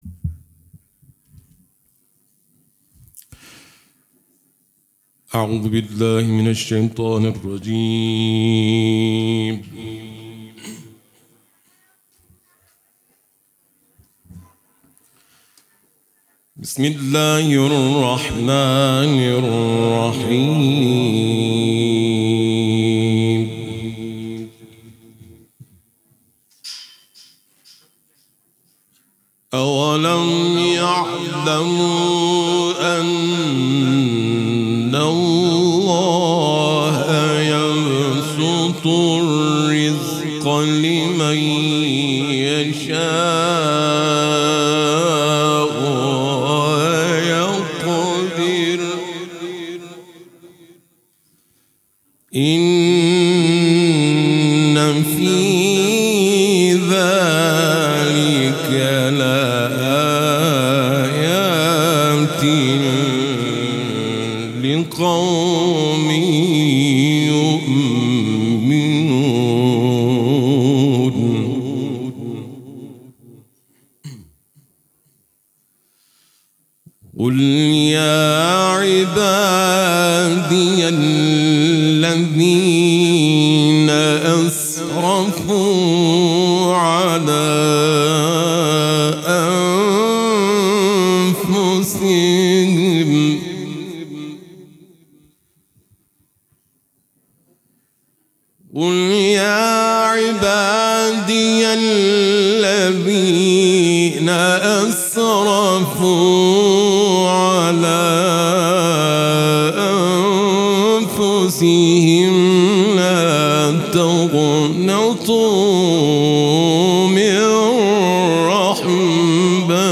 برچسب ها: صوت تلاوت ، سوره زمر ، تلاوت قرآن ، قاری ممتاز قرآن ، چهارمحال و بختیاری